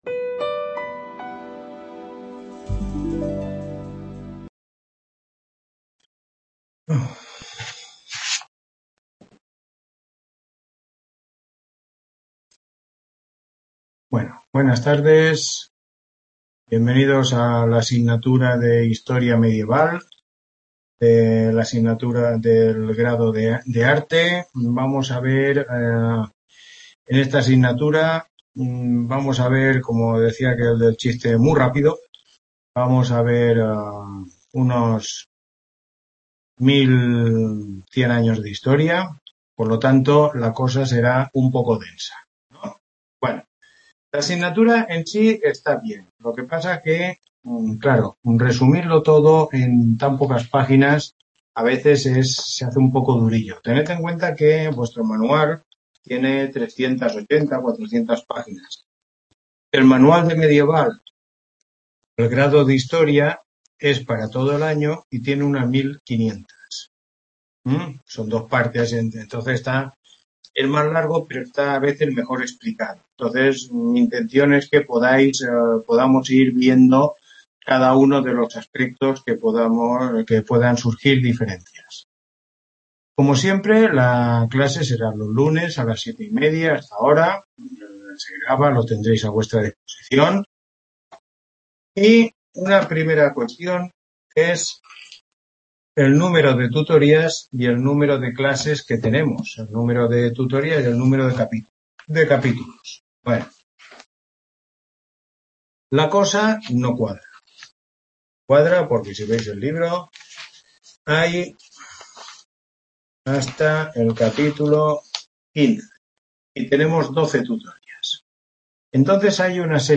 Tutoría 1